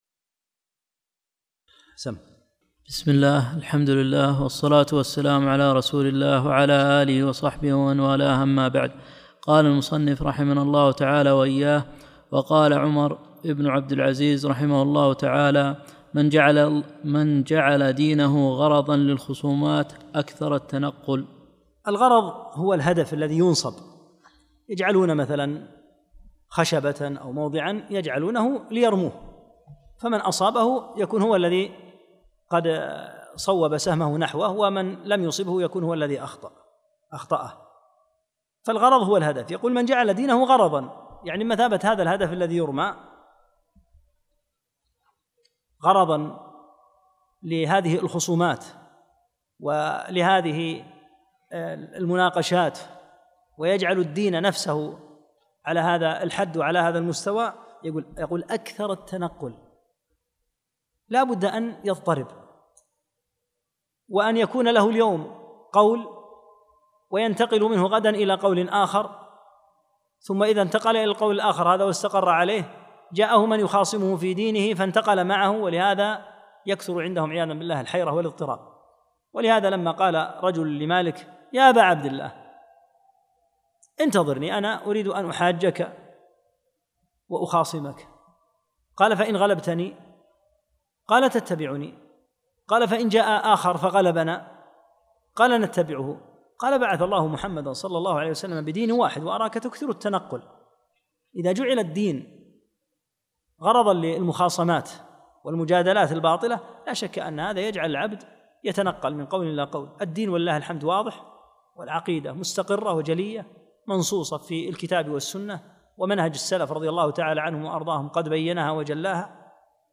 13- الدرس الثالث عشر